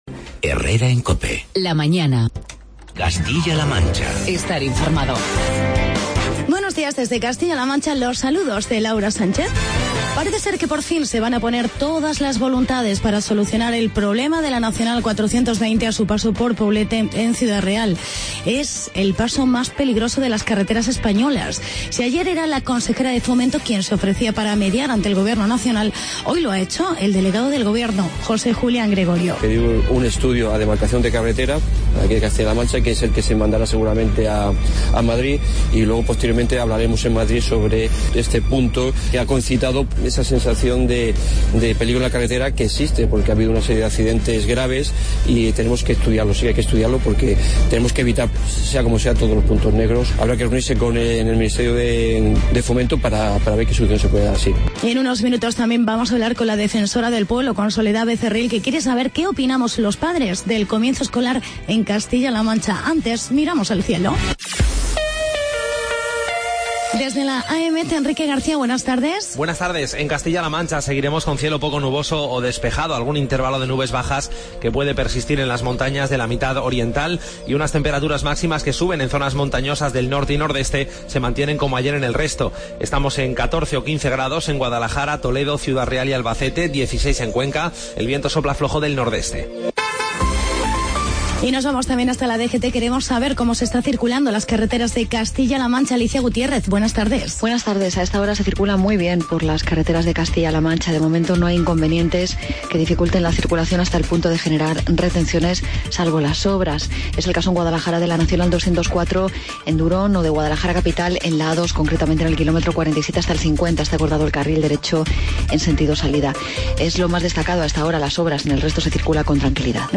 Actualidad y entrevista con Soledad Becerril, Defensora del Pueblo.